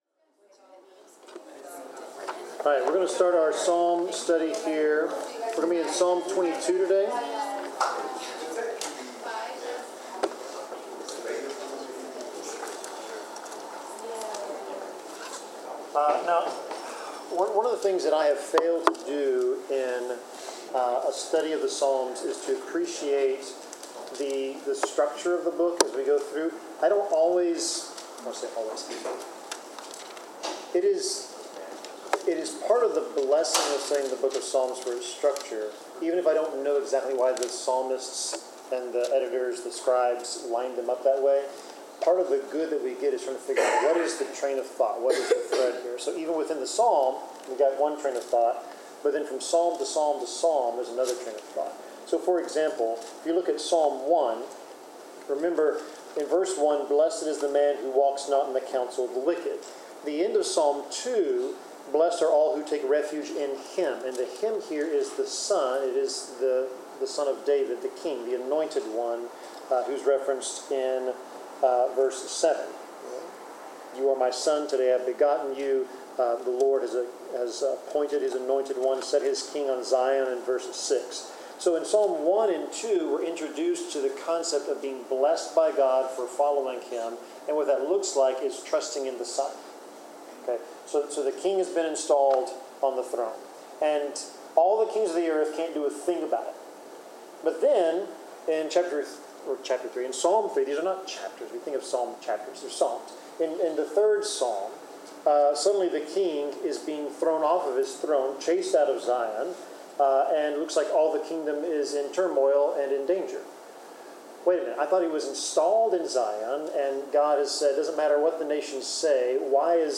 Bible class: Psalm 22
Passage: Psalm 22 Service Type: Bible Class